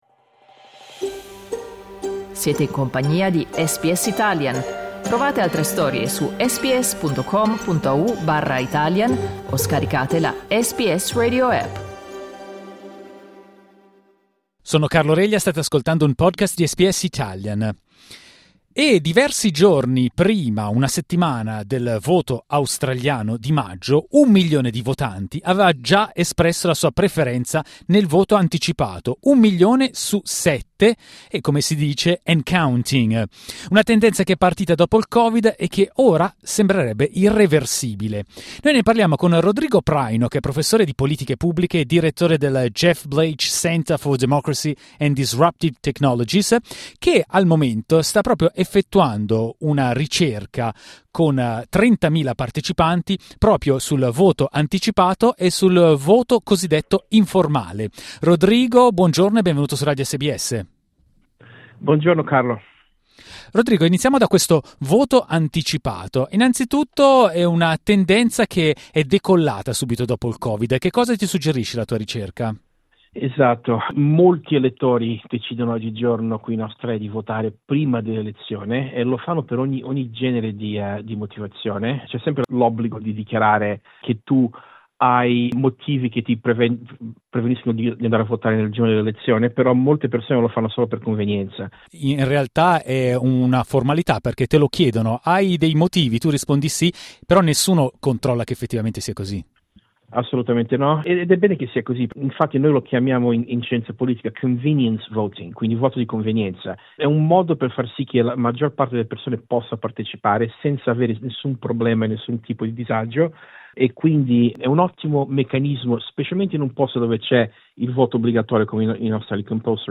SBS in Italiano